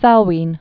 (sălwēn) or Nu·jiang (njyäng)